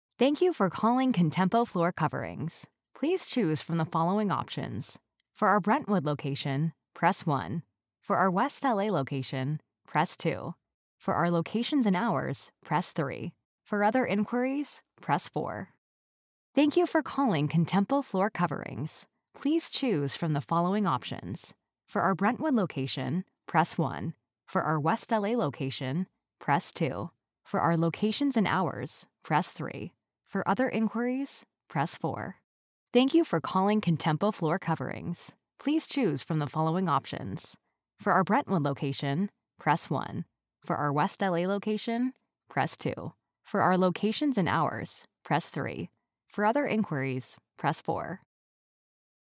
IVR
Meine Stimme reicht von einer gesprächigen, lässigen, freundlichen Gen Z über einen charmanten, nahbaren, coolen, komödiantischen besten Freund mit einem kleinen Krächzen bis hin zu einem energiegeladenen, neugierigen, furchtlosen, entzückenden kleinen Jungen.
Mikrofon: Sennheiser MKH 416
SENDEQUALITÄT IM HEIMSTUDIO